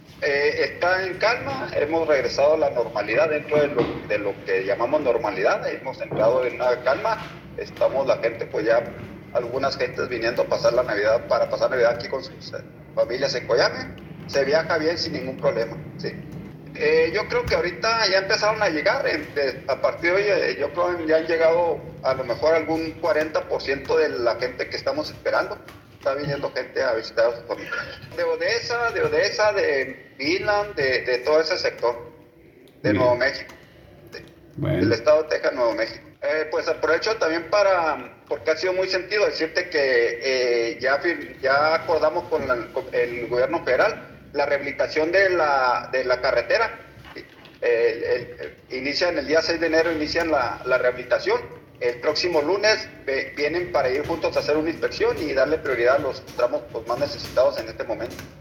Interrogado sobre la actualidad de esta problemática, Reyes anunció que la localidad «está en calma».